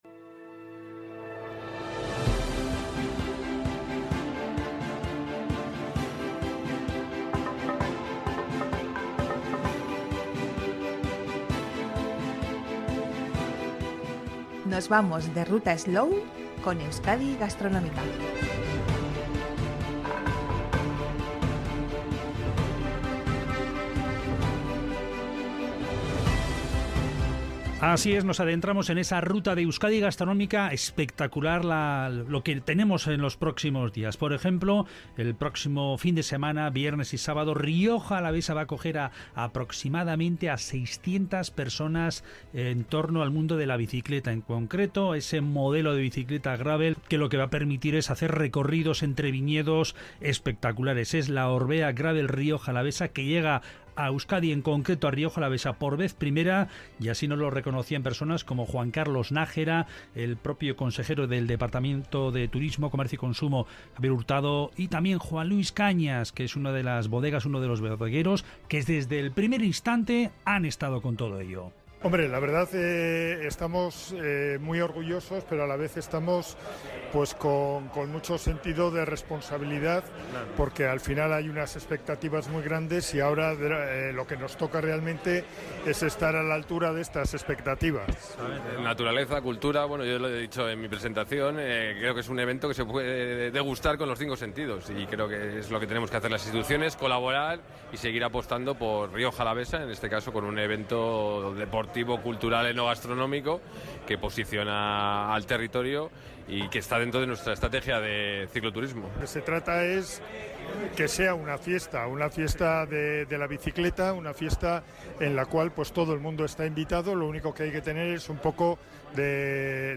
Audio: El marco incomparable del Mercado de Abastos de Vitoria-Gasteiz acogió la presentación de la I Orbea Gravel Rioja Alavesa